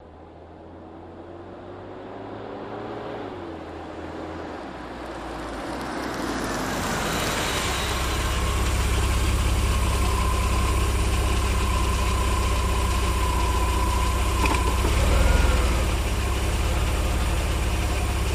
Corvette Pull In